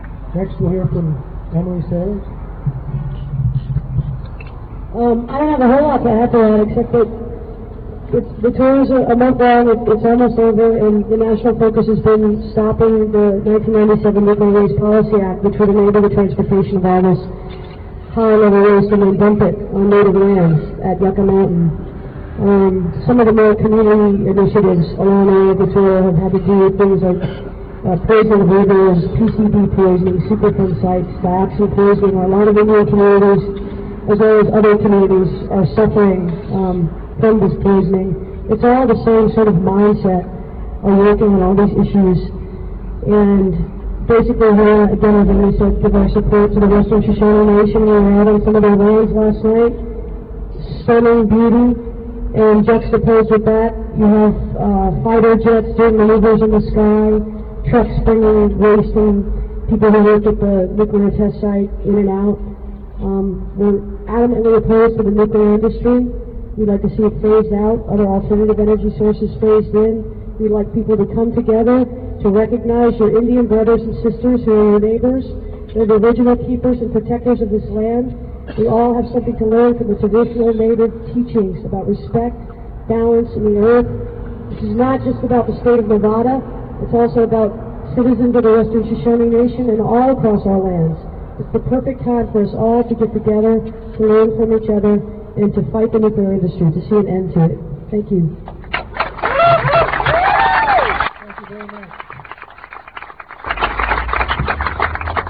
07. press conference - emily saliers (1:45)